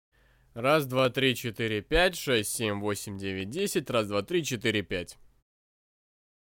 Треск при записи
Микрофон behringer c1 и звуковая карта behringer umc204hd. Столкнулся с проблемой - ни с того ни с сего начались трески при записи голоса в рипере. При тишине их нет.